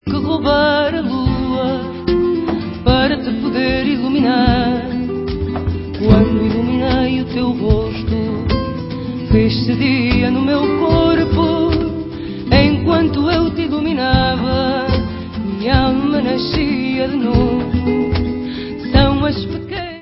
sledovat novinky v oddělení World/Fado